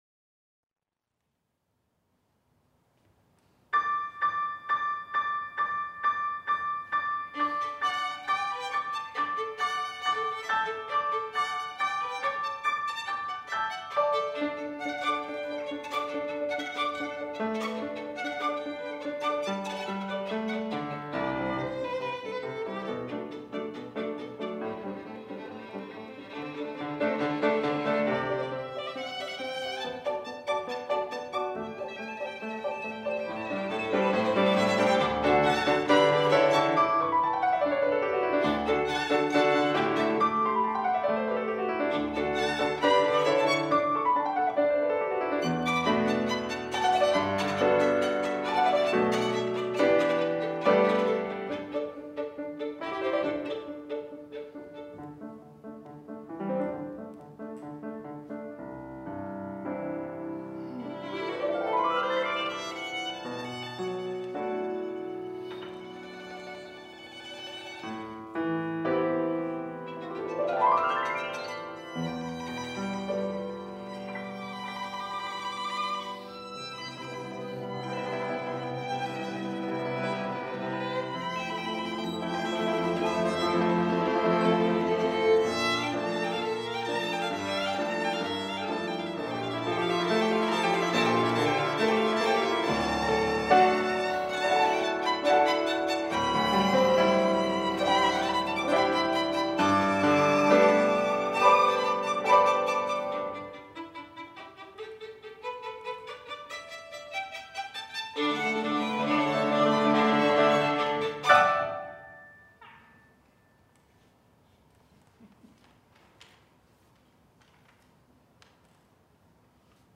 violins
piano.
A request was received for music for two violins and piano.
is an energetic, upwardly-focused movement